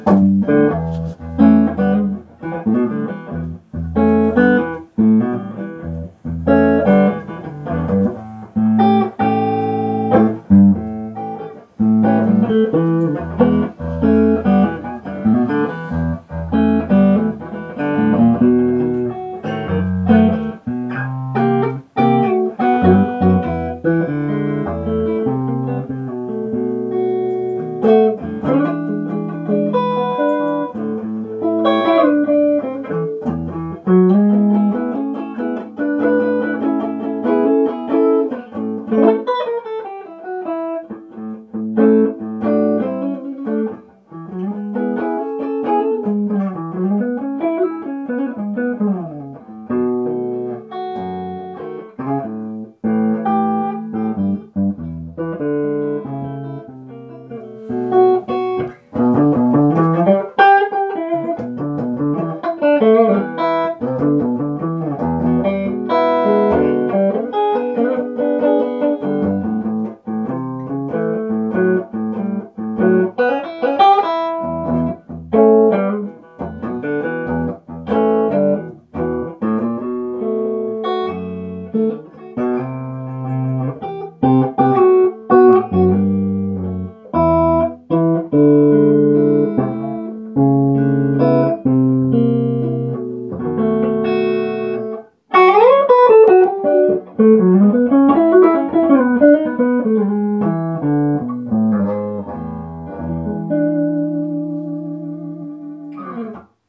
Uusi 2-kanavainen Rikstone H15 VM nuppi, jossa toinen kanava pohjautuu VOX AC15 EF86-kanavaan ja toinen Matchless Spitfireen.
Soundinäytteet (Gibson Les Paul äänitetty älypuhelimella):